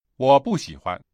（ウオ　ブー　シーフアン）